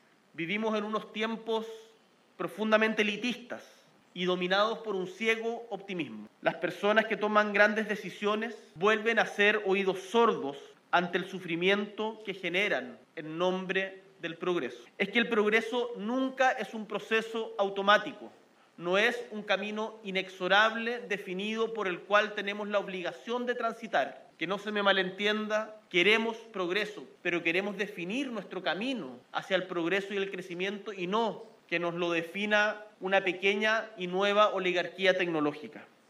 Luego, en una conferencia de prensa, habló sobre progresismo.